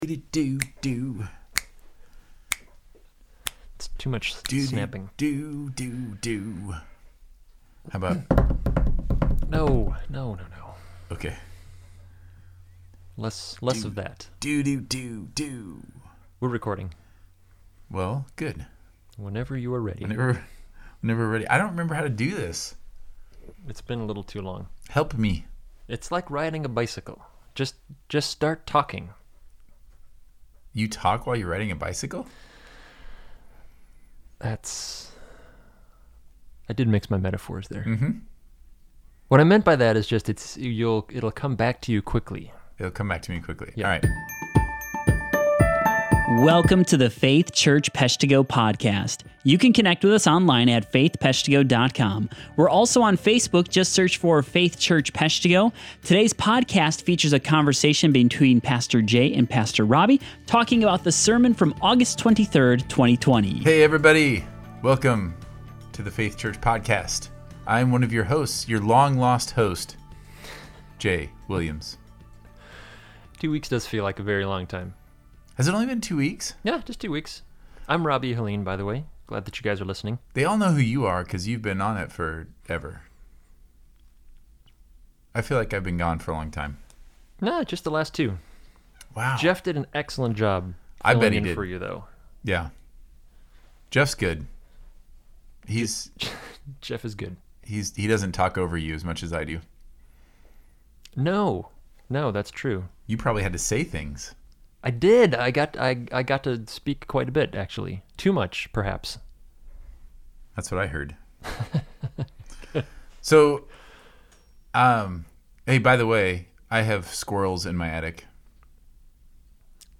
Podcast • 38 – Worship at the Park